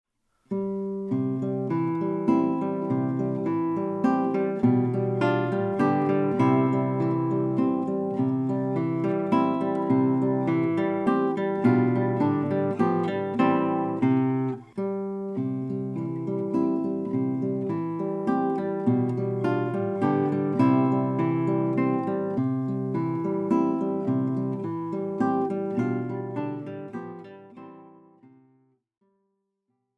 Gitarrenmusik aus Wien von 1800-1856
Besetzung: Gitarre